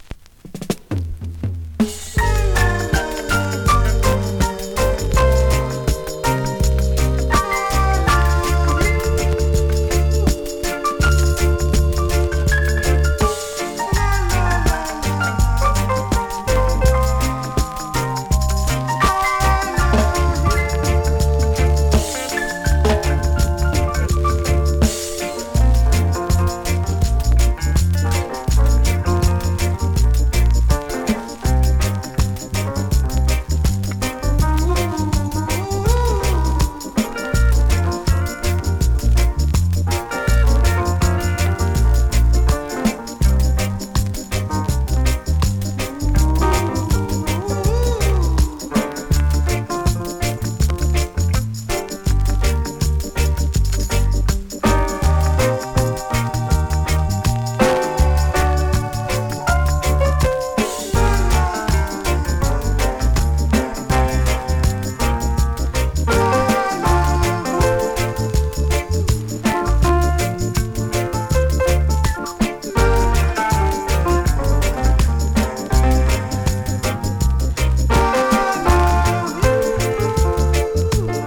NEW IN!SKA〜REGGAE
コンディションVG++(少し音割れ)
スリキズ、ノイズ比較的少なめで